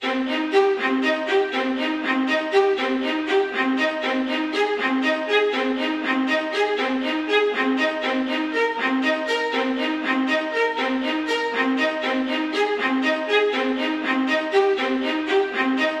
小提琴旋律伴奏弦乐
描述：小提琴旋律伴奏弦乐 管弦乐伴奏弦乐Sonivox VSTI PLUGIN
Tag: 120 bpm Electro Loops Violin Loops 2.69 MB wav Key : Unknown